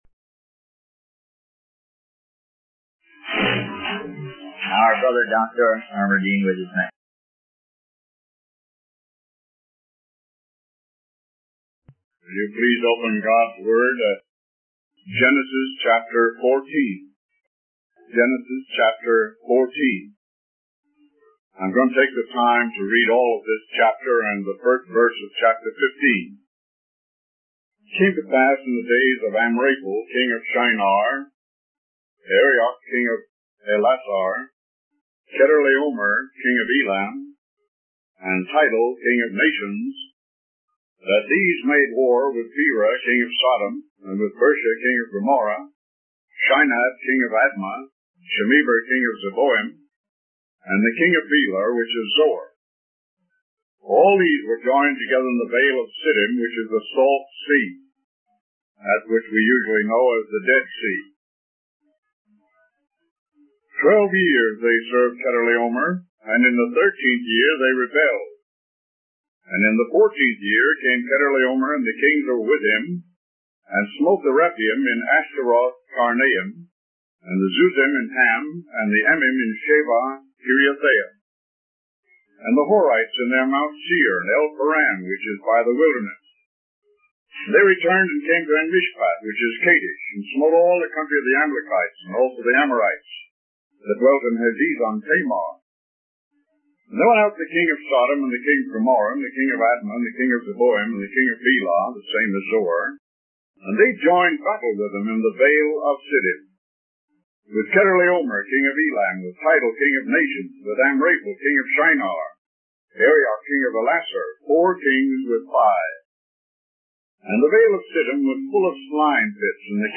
Bristol Conference 1969